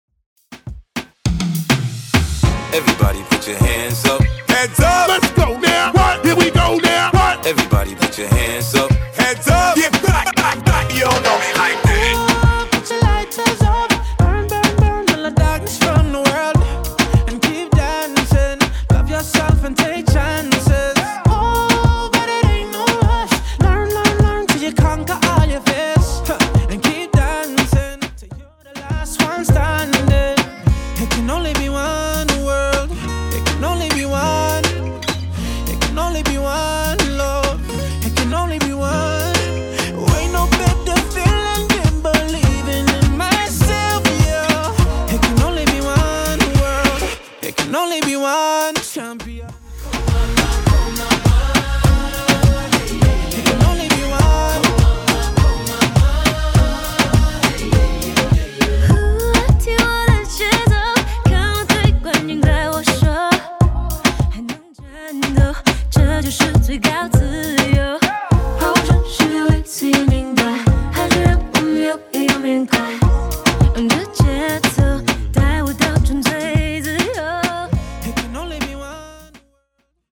REGGAETON , TOP40